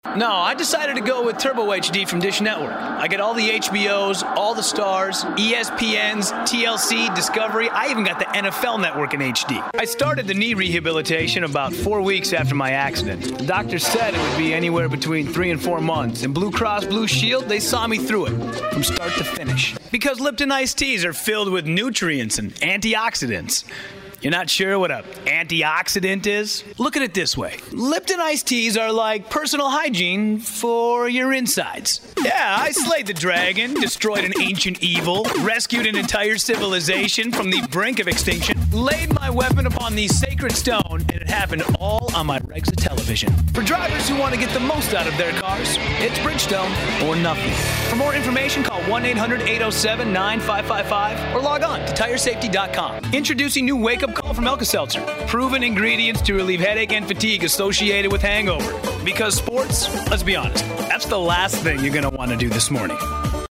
Young, Edgy, Modern